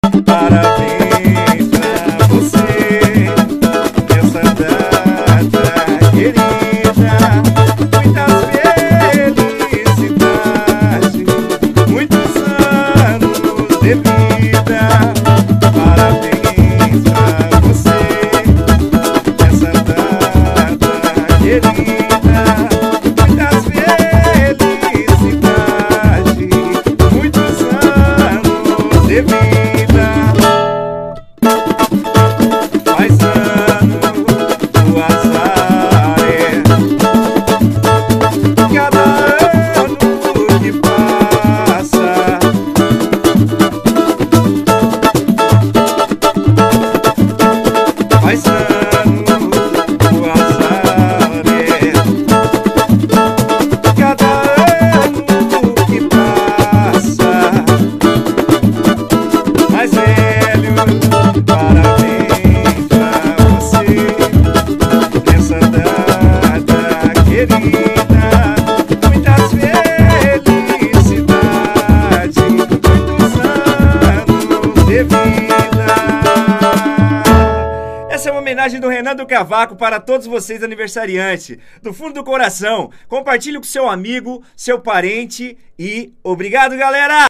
Parabens-Pra-Voce-Versao-em-Pagode-MP3-para-Download.mp3